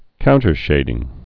(kountər-shāding)